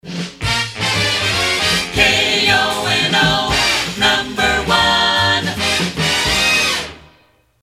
NOTE: These jingle samples are from my private collection.